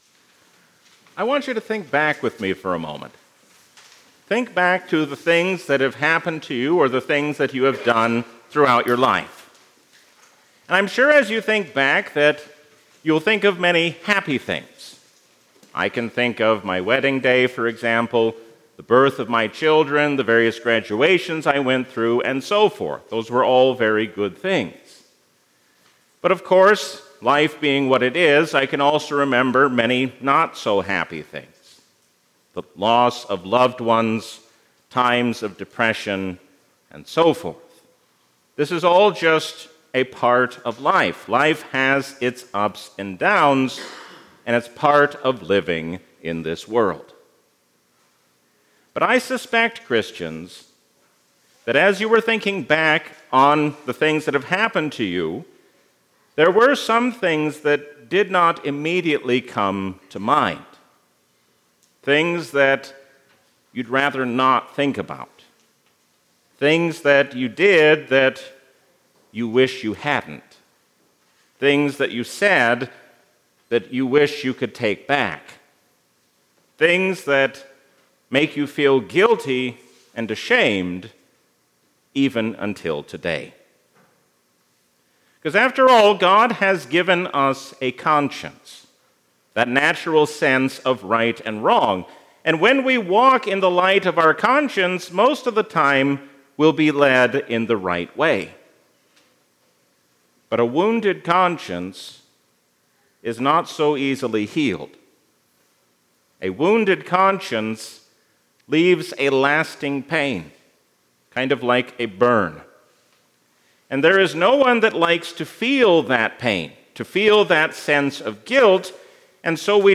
A sermon from the season "Trinity 2025." Peter's miracles show us that God is still at work in His Church.